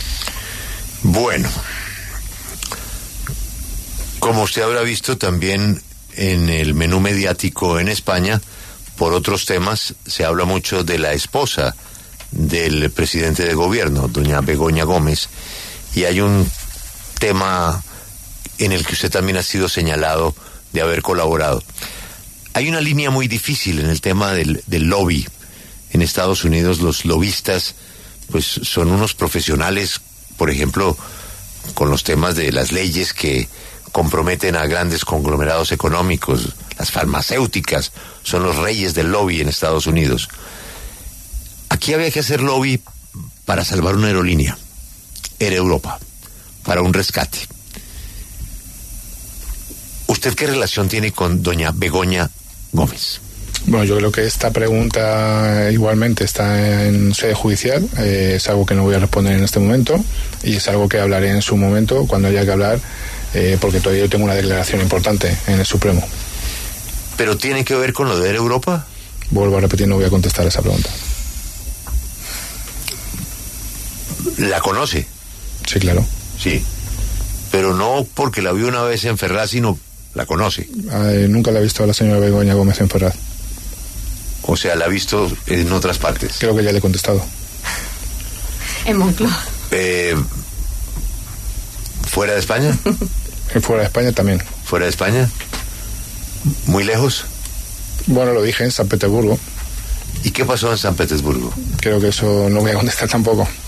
En la entrevista, a Víctor de Aldama se le preguntó acerca de la esposa del presidente Pedro Sánchez, Begoña Gómez, quien recientemente se ha visto envuelta en una polémica por una presunta colaboración con lobistas para rescatar a una aerolínea.